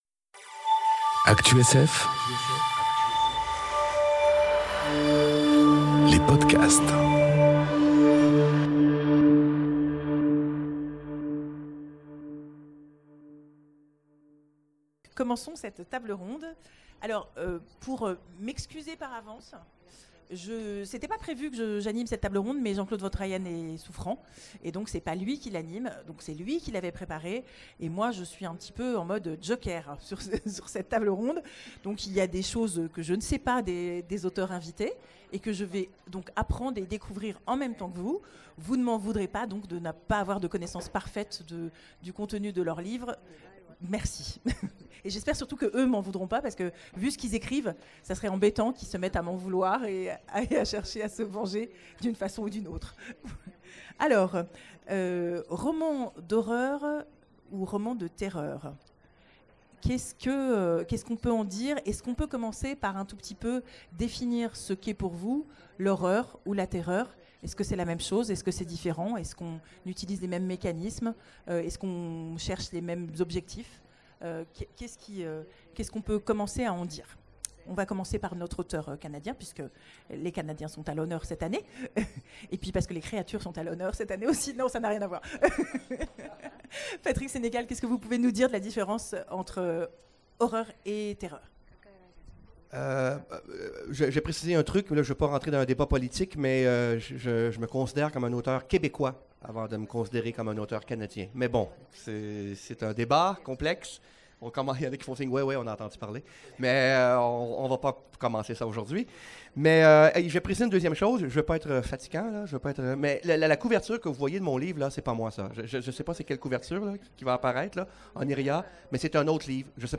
Conférence Récits de terreur... Romans d'horreur enregistrée aux Imaginales 2018